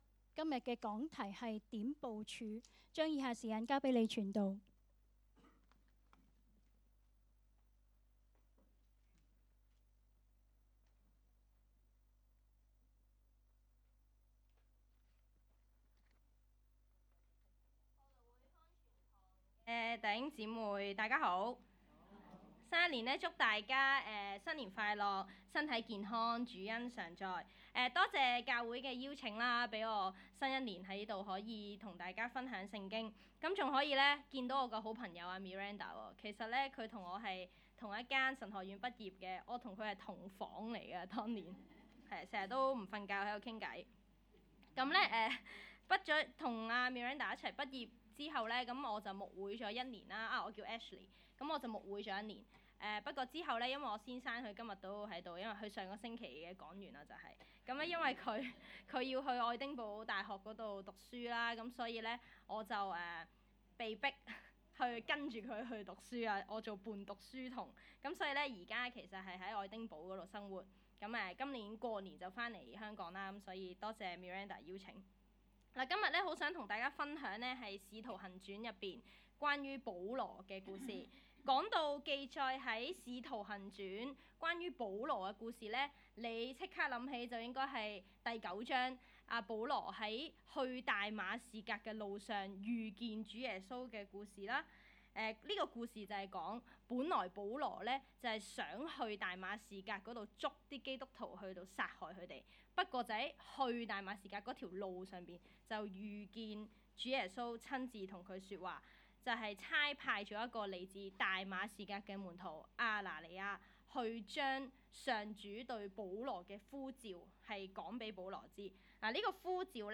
2025年2月1日及2日崇拜